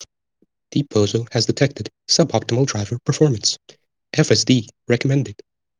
deep-bozo-has-detected.wav